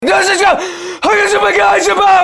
Play, download and share stamper scream2 original sound button!!!!
stamper_scream_02_P2Tap6B.mp3